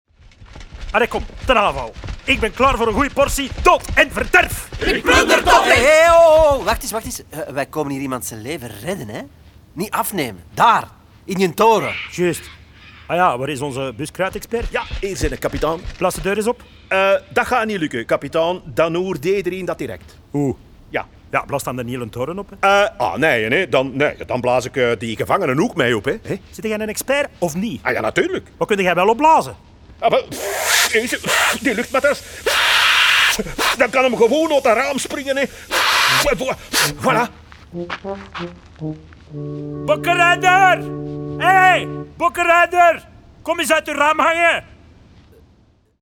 De Bokrokker is niet alleen een boek om te lezen, maar ook een hoorspel boordevol grappen en vrolijke liedjes.